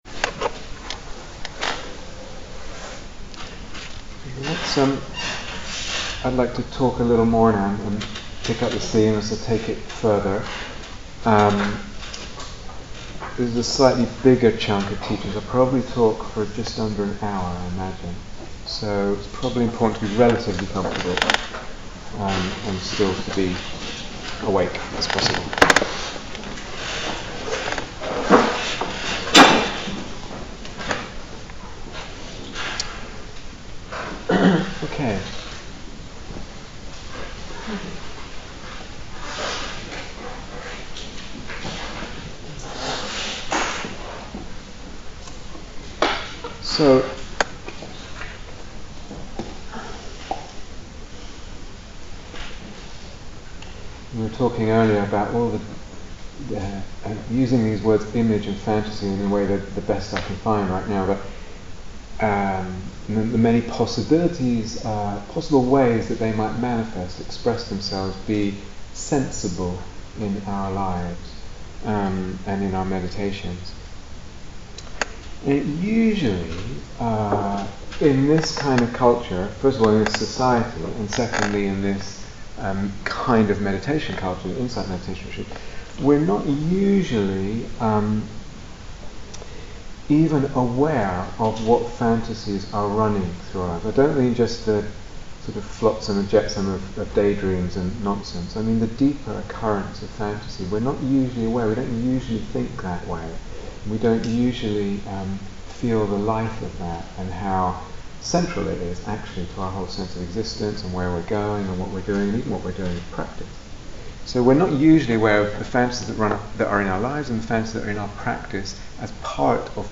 Releasing the Self, Freeing its Demons (Part 3) Download 0:00:00 56:37 Date 5th October 2014 Retreat/Series Day Retreat, London Insight 2014 Transcription I'd like to talk a little more now, pick up this theme and take it further. This is a slightly bigger chunk of teaching.